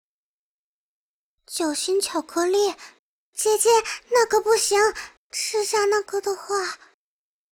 贡献 ） 协议：Copyright，人物： 碧蓝航线:平海语音 2021年2月4日